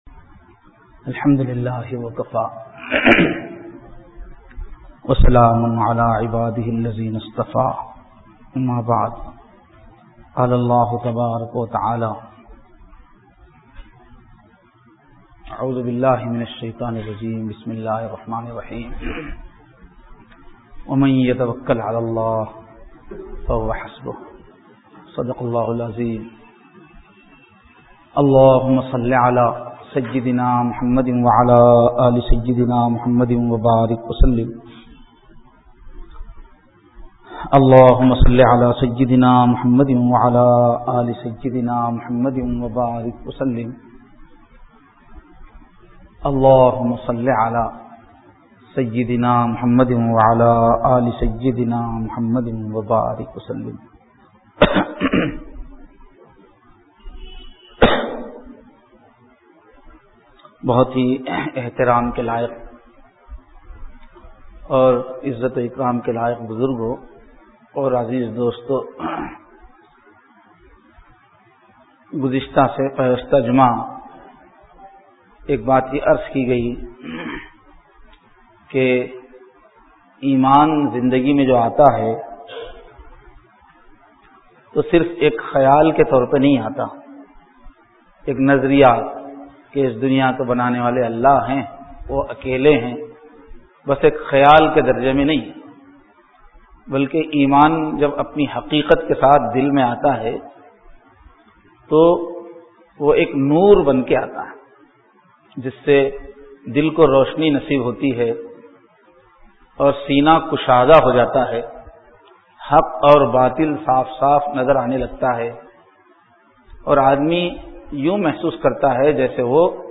Bayanath , Jummah